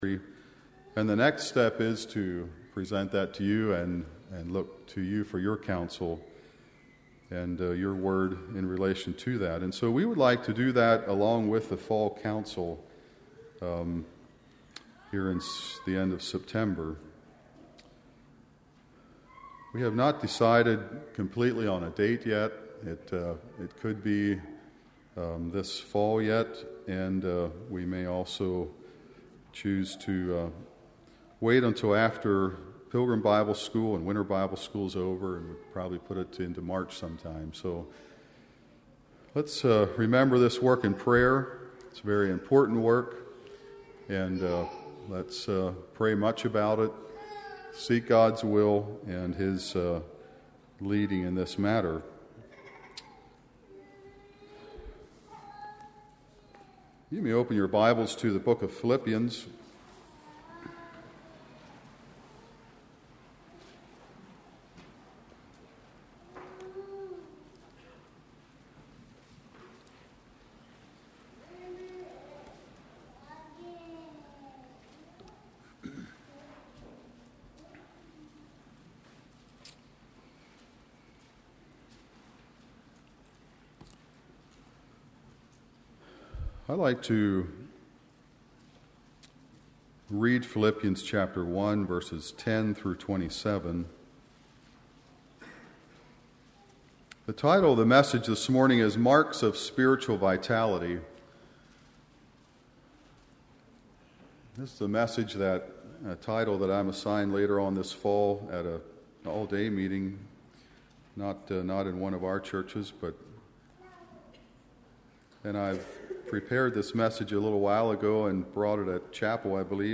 Spiritual vitality is necessary for a successful Christian life. This sermon gives ten points to help with spiritual vitality.